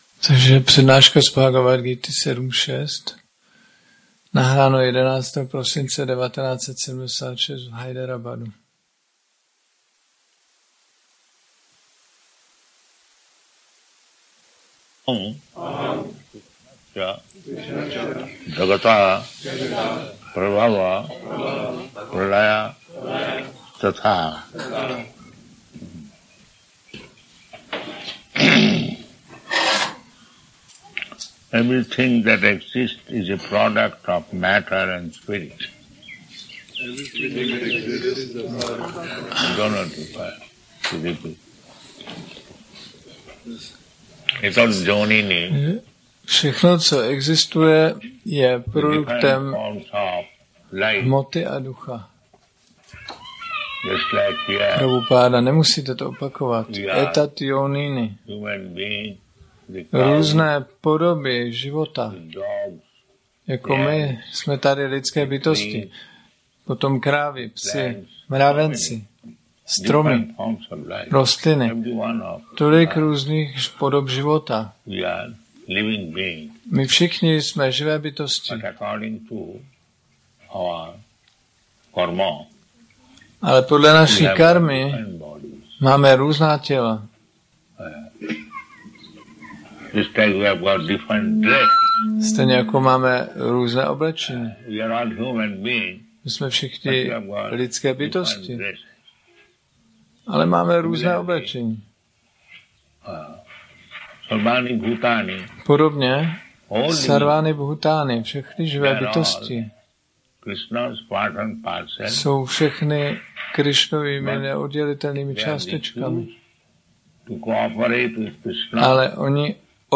1975-12-11-ACPP Šríla Prabhupáda – Přednáška BG-7.6 Hyderabad